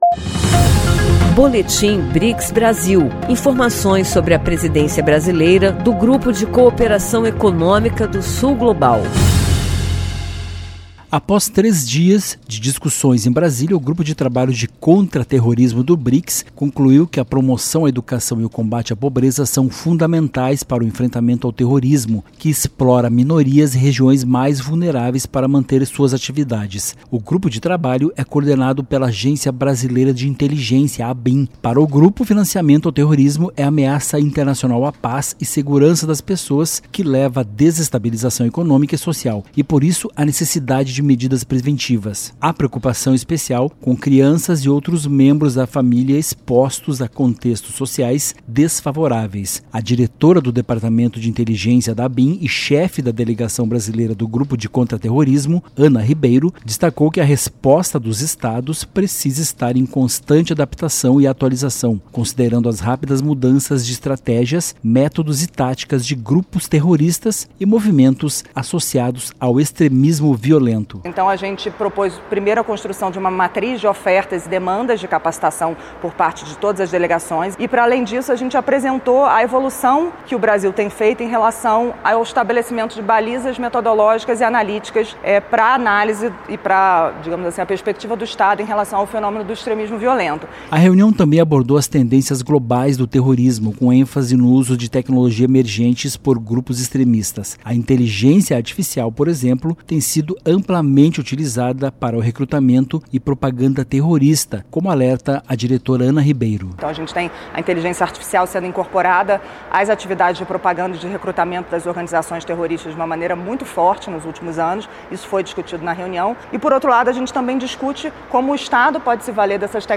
O ministro Mauro Vieira e o sherpa Mauricio Lyrio destacaram prioridades do Brasil, como saúde, comércio, clima e inteligência artificial. Ouça a reportagem e saiba mais.